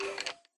Sound / Minecraft / mob / skeleton3